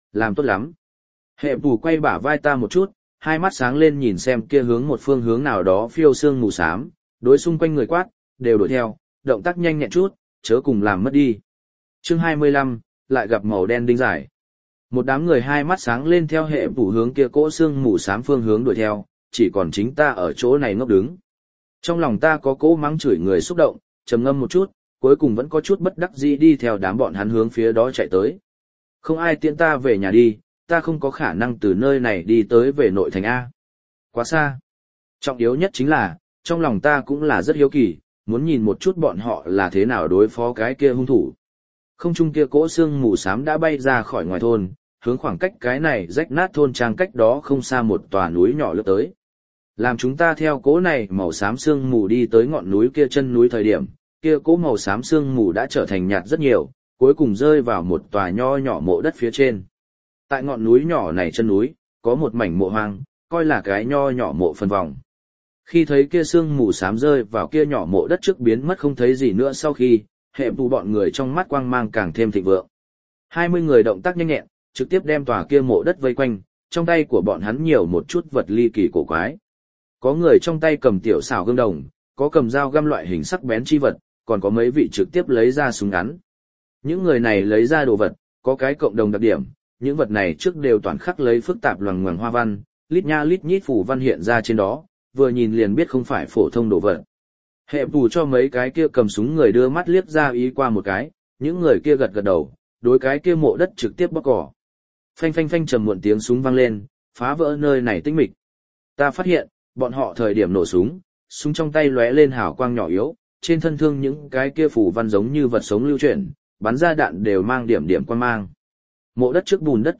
Phải Lòng Âm Phủ Tiểu Kiều Thê Audio - Nghe đọc Truyện Audio Online Hay Trên TH AUDIO TRUYỆN FULL